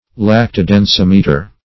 Search Result for " lactodensimeter" : The Collaborative International Dictionary of English v.0.48: Lactodensimeter \Lac`to*den*sim"e*ter\, n. [L. lac, lactis, milk + E. densimeter.] A form of hydrometer, specially graduated, for finding the density of milk, and thus discovering whether it has been mixed with water or some of the cream has been removed.
lactodensimeter.mp3